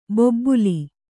♪ bobbuli